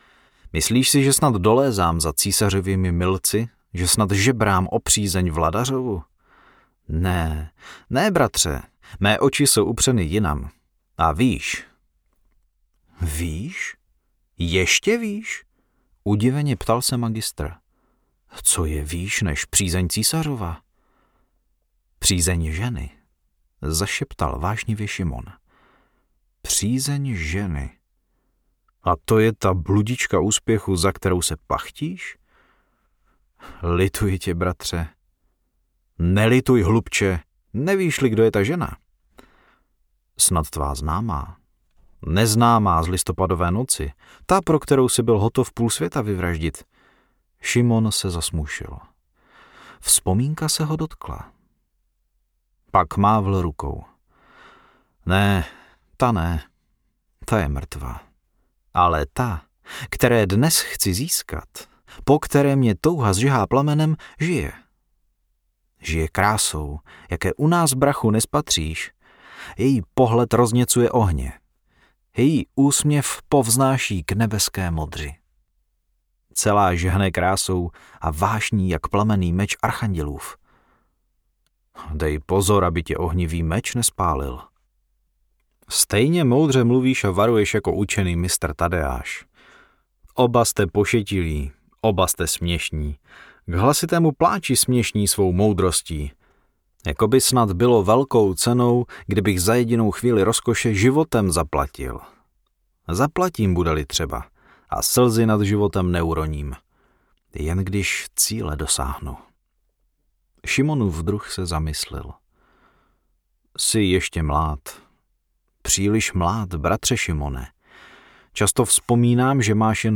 Kouzelné zrcadlo audiokniha
Ukázka z knihy